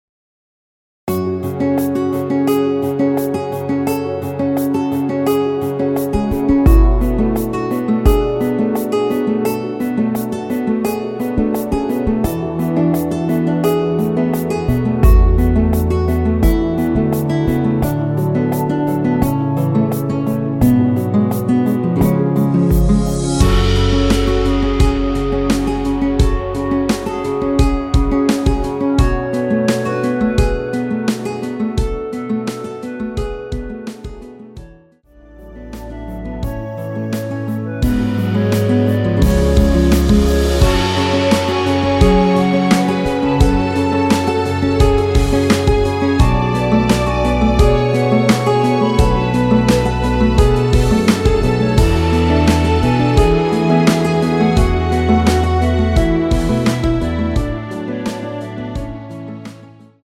원키에서(+3)올린 멜로디 포함된 MR입니다.
F#m
앞부분30초, 뒷부분30초씩 편집해서 올려 드리고 있습니다.
중간에 음이 끈어지고 다시 나오는 이유는